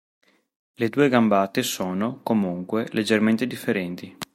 co‧mùn‧que
/koˈmun.kwe/